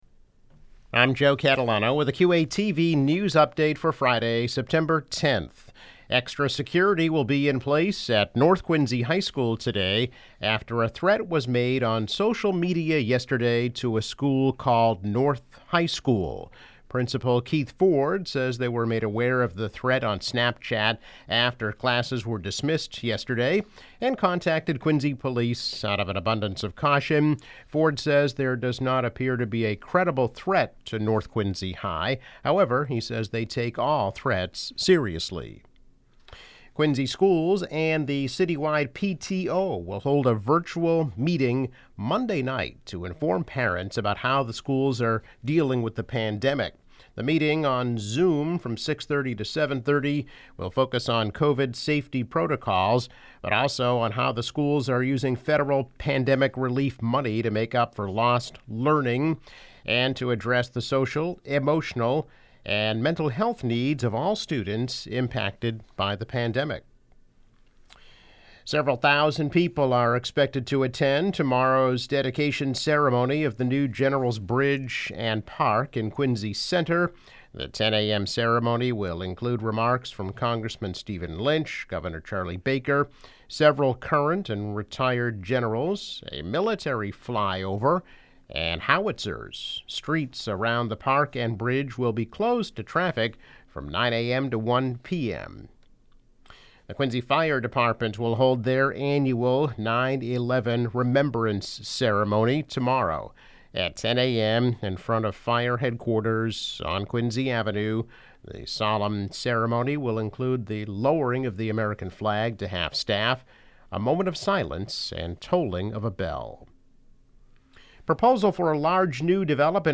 News Update - September 10, 2021